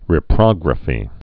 (rĭ-prŏgrə-fē)